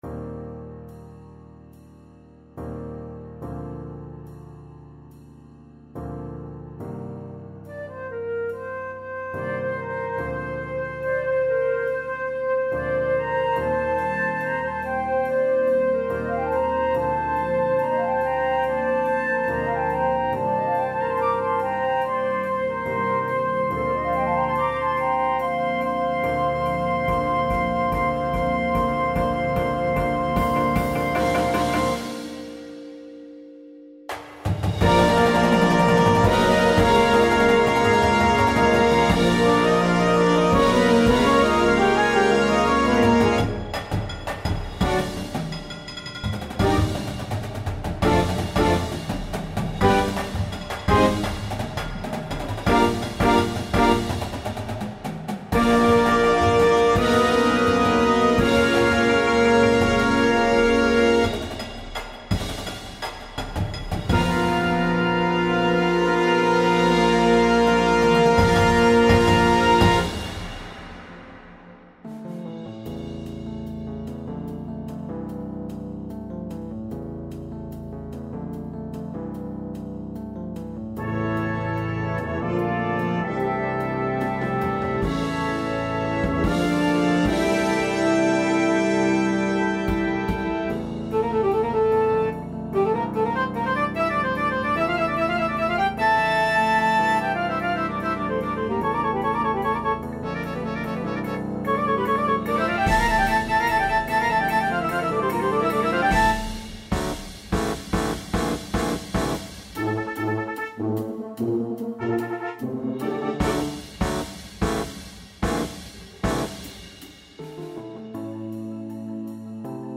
a vibrant exploration of growth and renewal